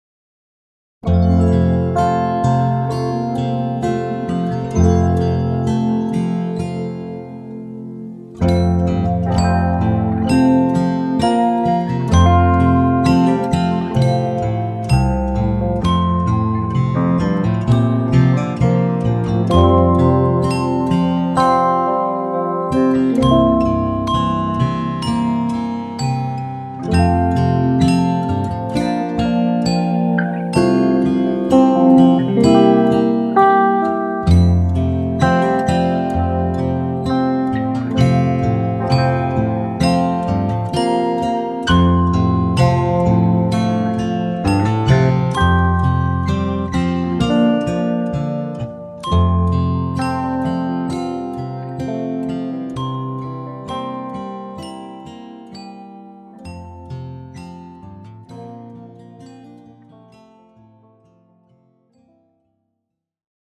Latviešu tautas dziesma Play-along.
Spied šeit, lai paklausītos Demo ar melodiju